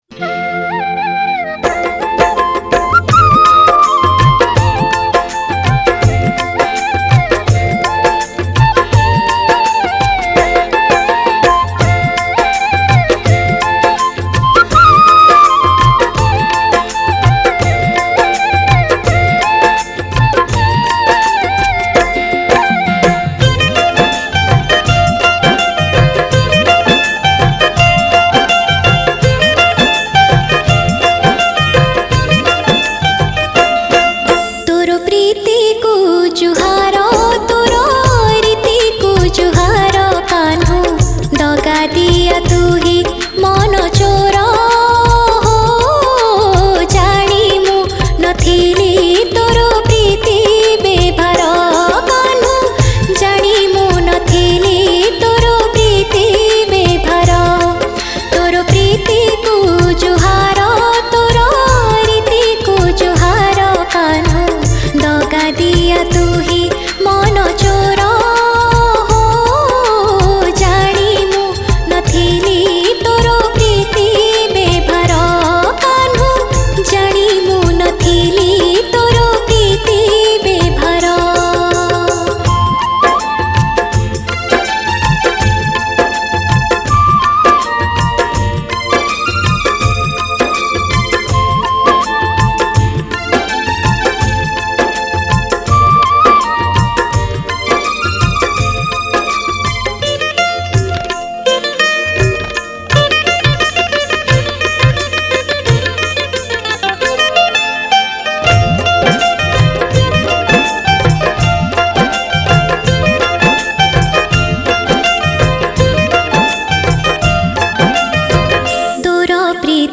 Odia Bhajan Song 2022 Songs Download